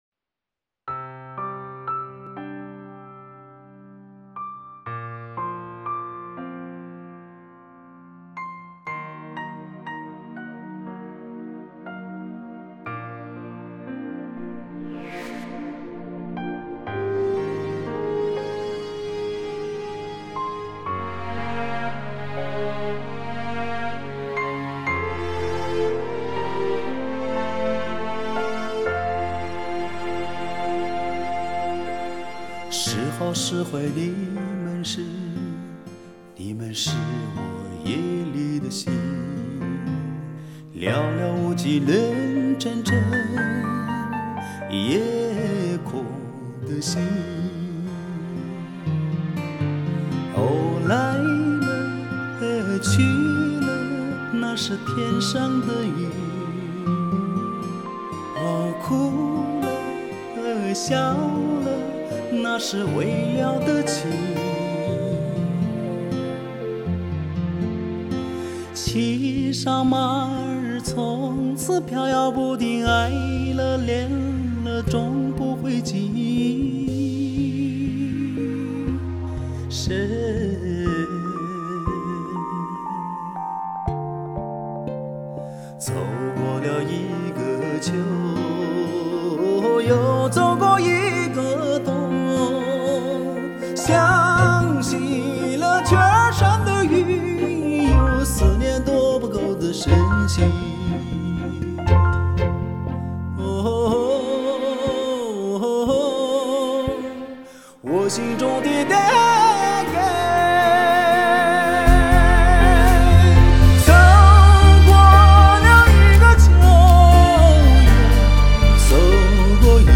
他高远深情的嗓音